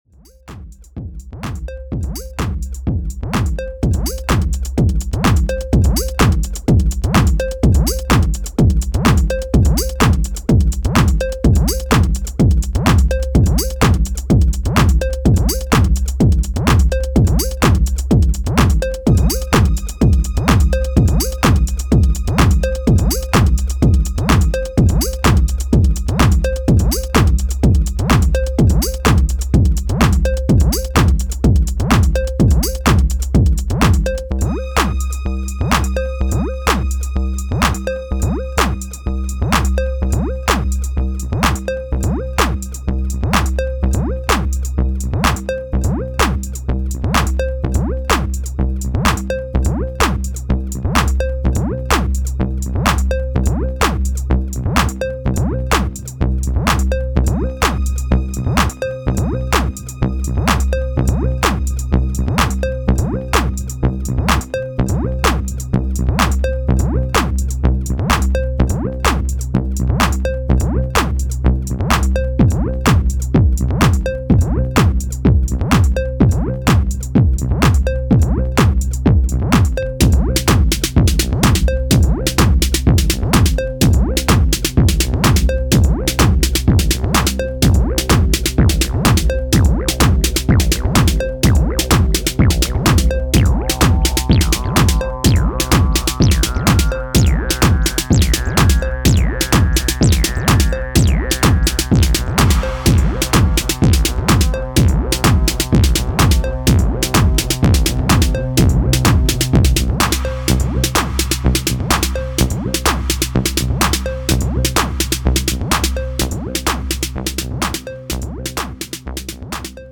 ロウなアシッド・ハウス集となっています